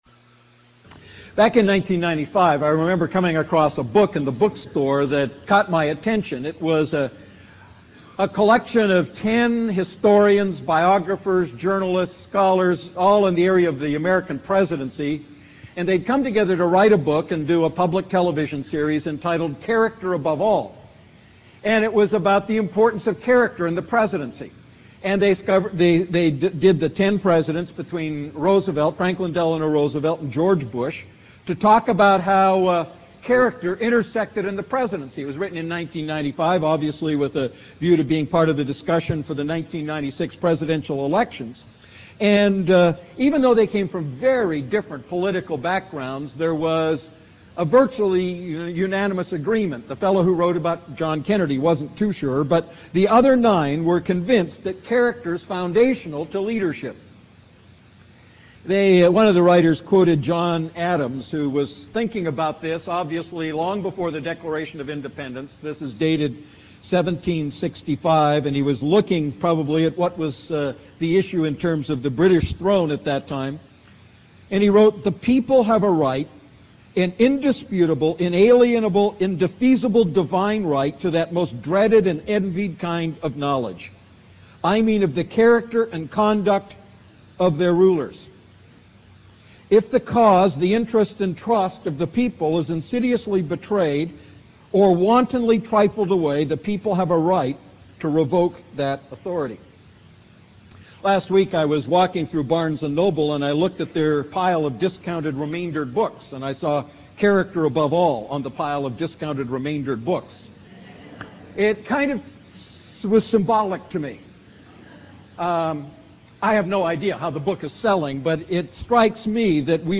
A message from the series "I John Series."